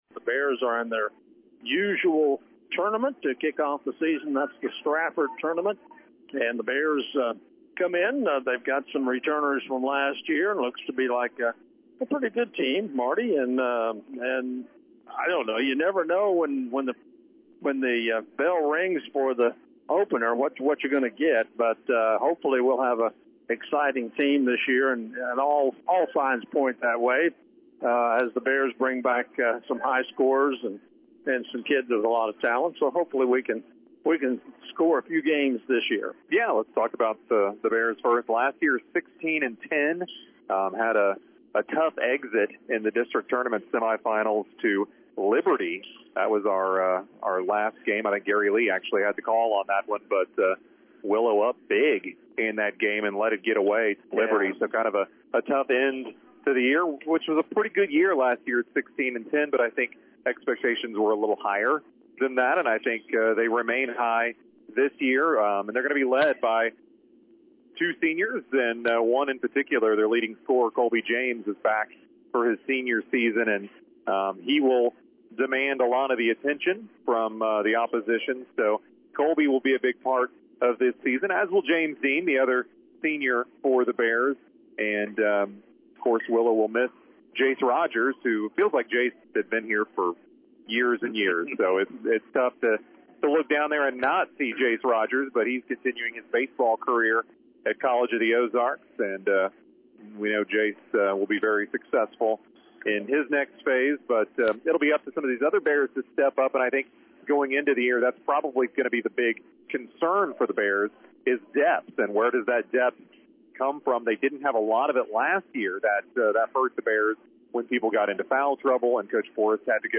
Game Audio Below: The Willow Springs Bears took on The Sparta Trojans in their first season Basketball Matchup from The Strafford Invitational Tournament on Tuesday.
The Willow Springs Bears took on The Sparta Trojans in their first season Basketball Matchup from The Strafford Invitational Tournament on Tuesday.